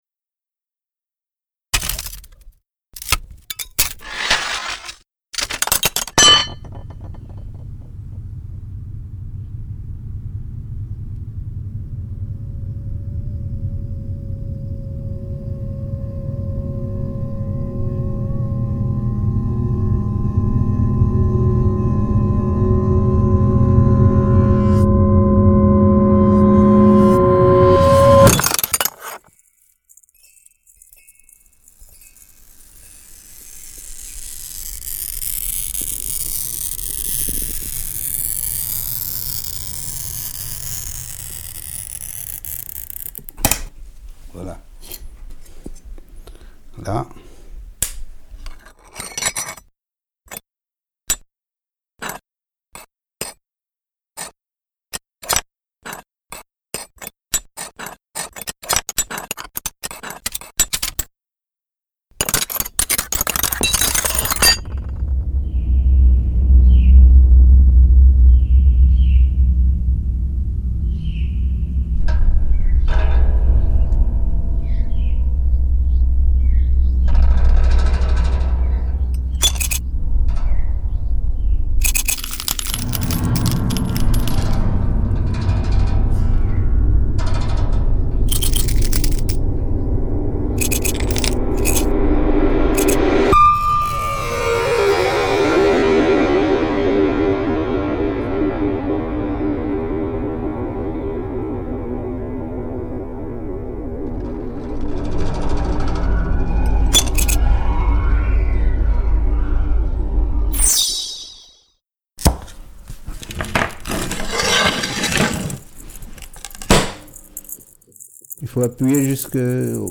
Immatérialité du son et sonorité du verre Entrez dans le verre avec vos oreilles …
Courte pièce électroacoustique